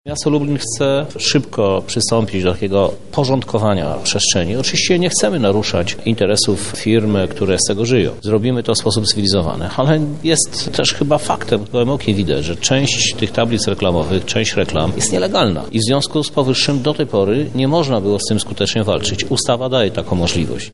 W Lublinie odbyła się dyskusja o nowej ustawie krajobrazowej.
W dyskusji wzięli udział również samorządowcy z województwa lubelskiego, wśród nich prezydent Lublina Krzysztof Żuk: